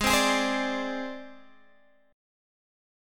G#Mb5 chord